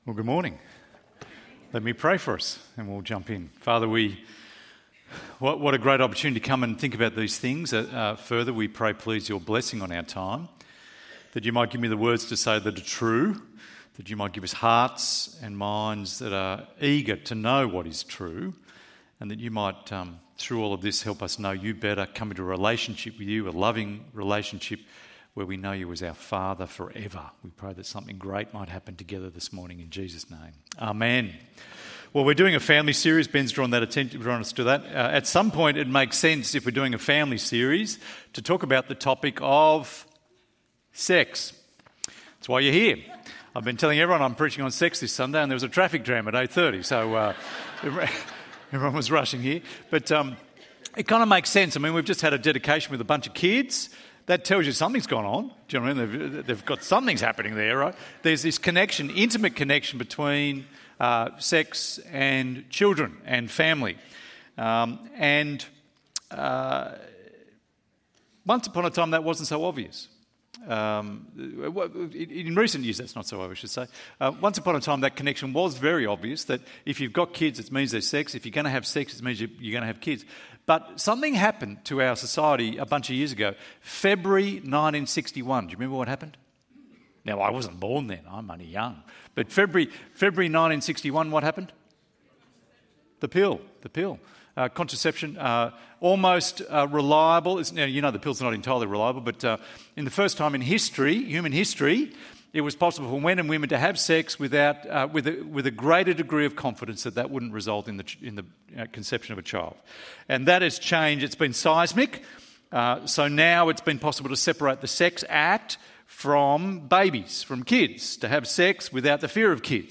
Sex and Marriage ~ EV Church Sermons Podcast